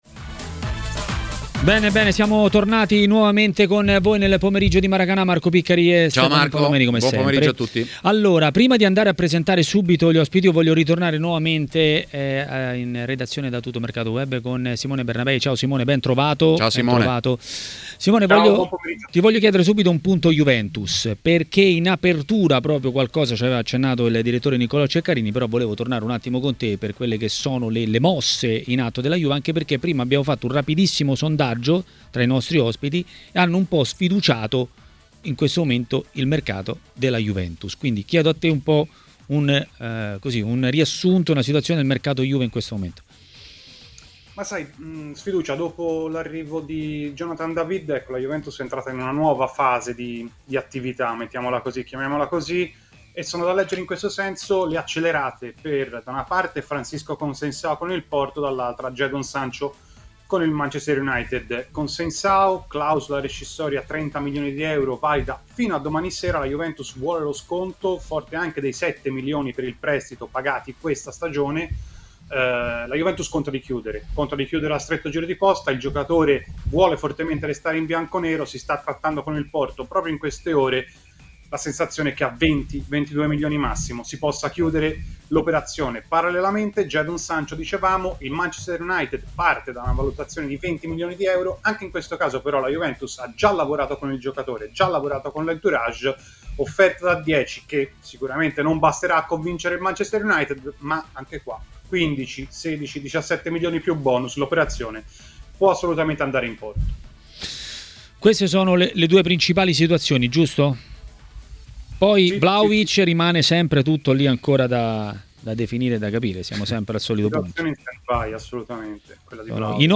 A intervenire in diretta a Maracanà, nel pomeriggio di TMW Radio, è stato l'ex calciatore Antonio Di Gennaro.